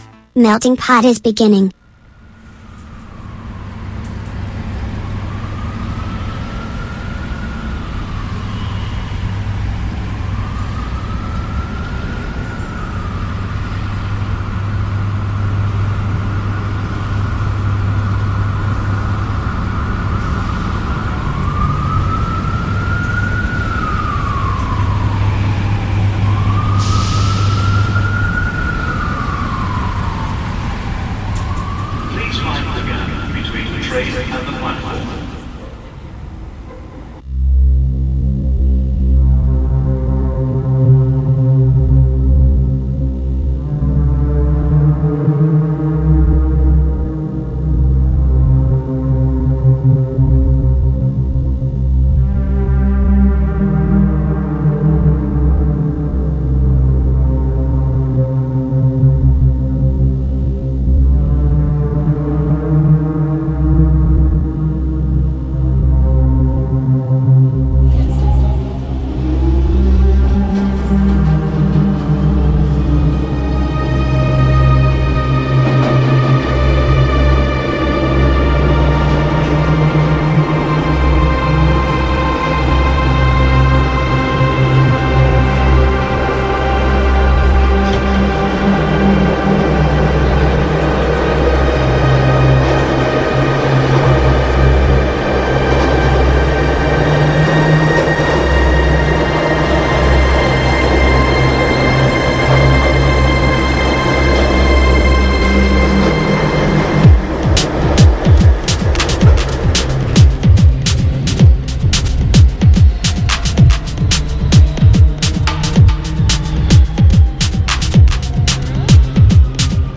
MUSICA NOTIZIE INTERVISTE A MELTINGPOT | Radio Città Aperta